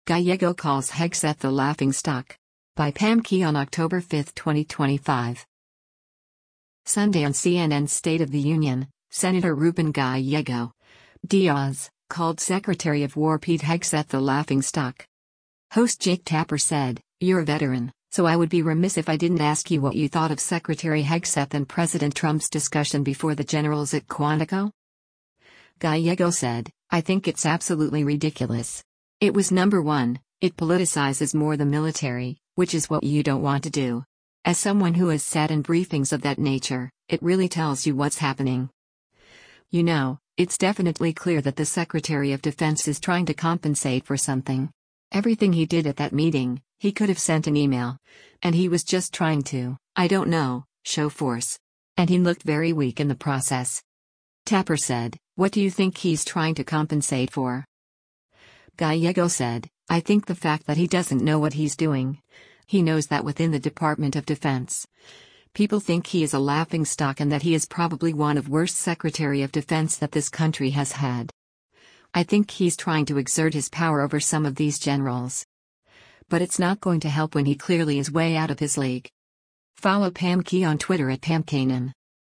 Sunday on CNN’s “State of the Union,” Sen. Ruben Gallego (D-AZ) called Secretary of War Pete Hegseth a “laughing stock.”